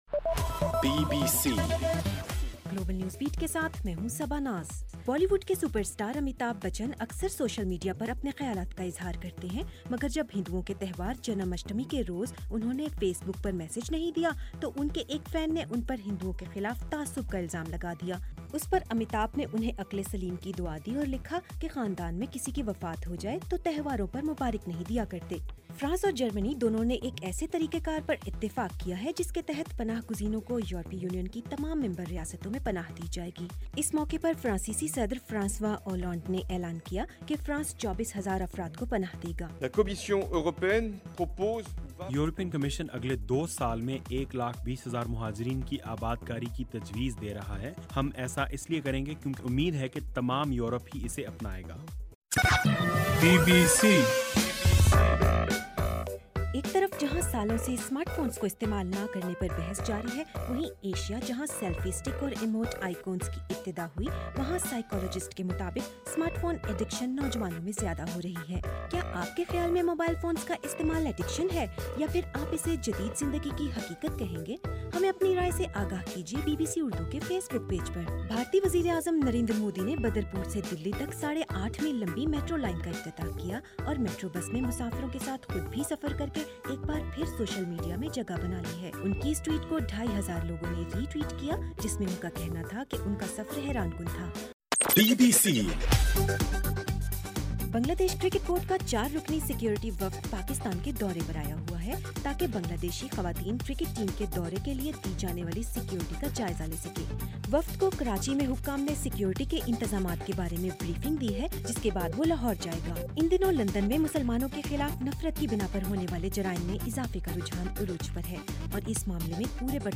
ستمبر7: رات 9 بجے کا گلوبل نیوز بیٹ بُلیٹن